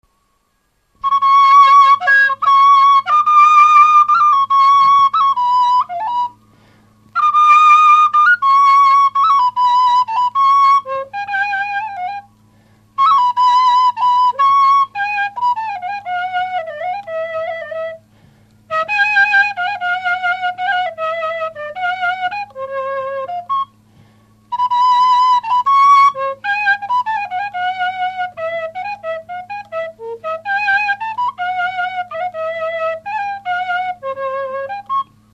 Dallampélda: Hangszeres felvétel
Erdély - Udvarhely vm. - Székelyszentmihály
furulya
Műfaj: Csárdás
Stílus: 1.1. Ereszkedő kvintváltó pentaton dallamok
Kadencia: 7 (5) b3 1